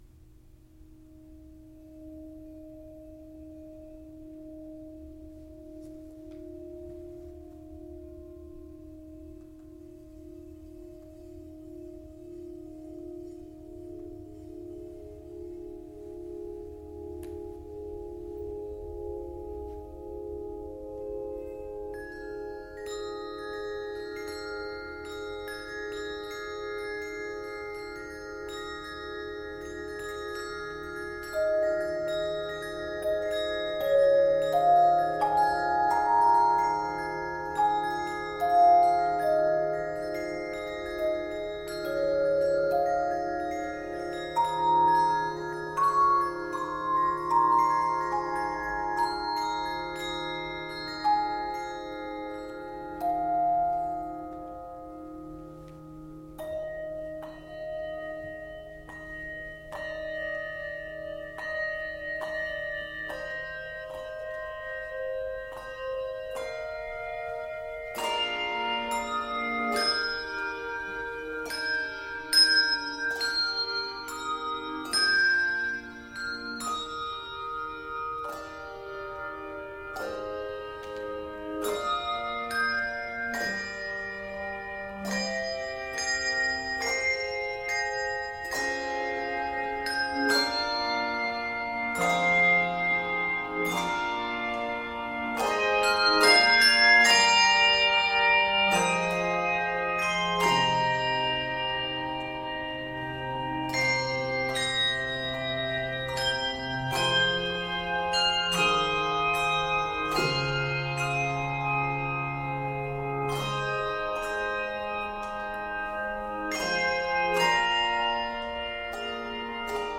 It is 75 measures and is set in Eb Major and C Major.
Octaves: 3-5